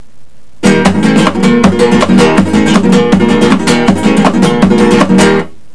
You'll strum with a repeating up and down hand motion - down stroke with middle and ring finger; up stroke with back of thumb.
Example Five: La Bamba (single at tempo, 144k)